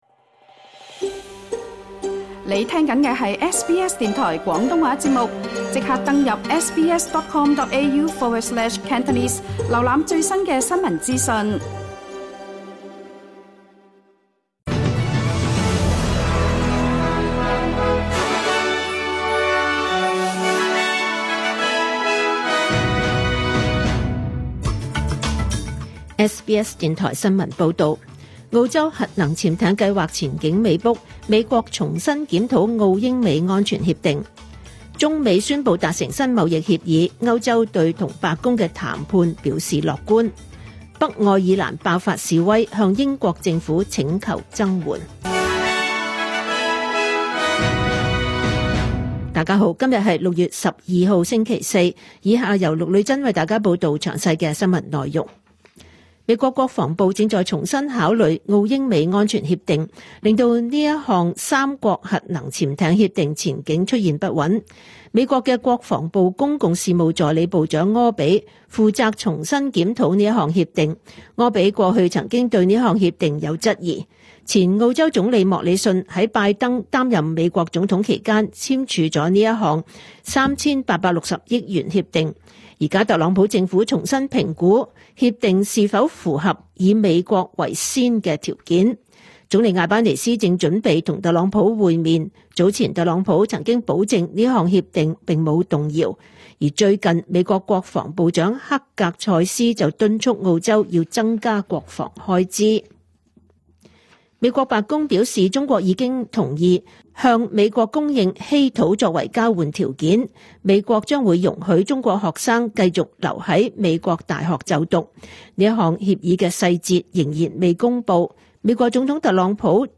2025 年 6 月 12 日 SBS 廣東話節目詳盡早晨新聞報道。